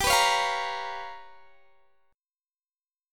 G7#9b5 Chord
Listen to G7#9b5 strummed